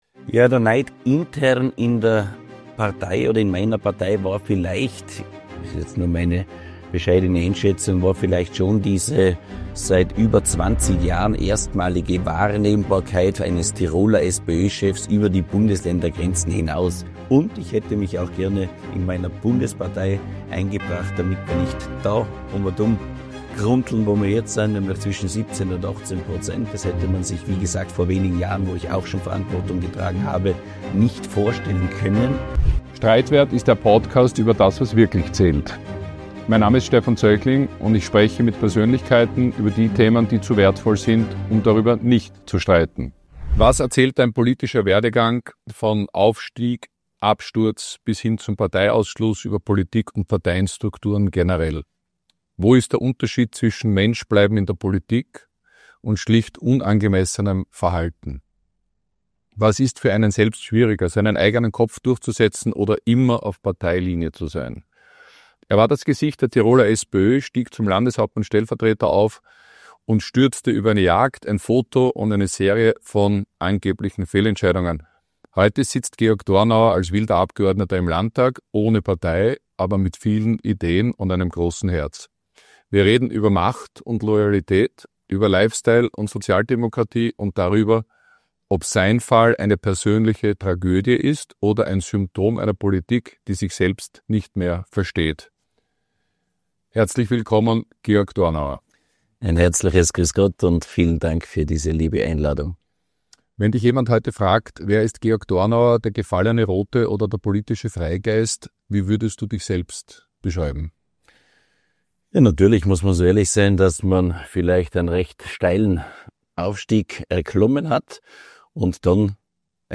Ein Gespräch über Eitelkeiten, Neid, mediale Skandalisierung und die Frage, wie viel Platz in der Politik für den eigenen Kopf ist.